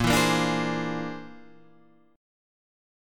Bb9b5 chord